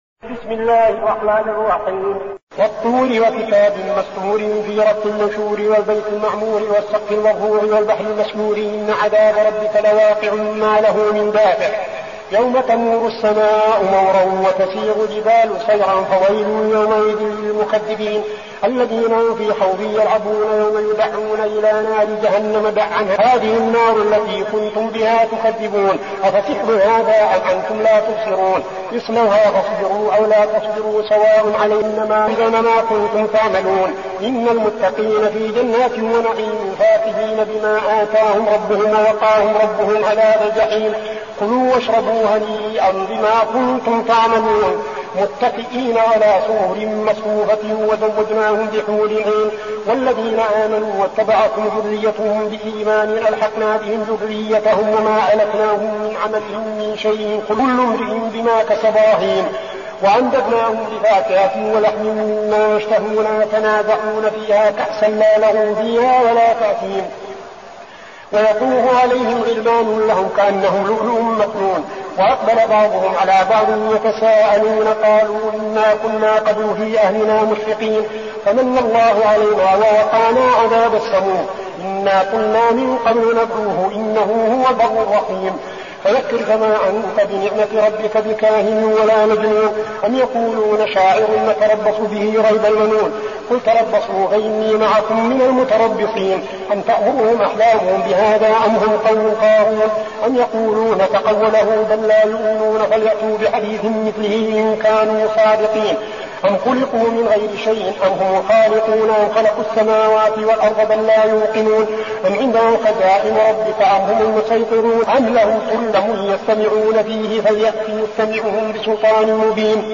المكان: المسجد النبوي الشيخ: فضيلة الشيخ عبدالعزيز بن صالح فضيلة الشيخ عبدالعزيز بن صالح الطور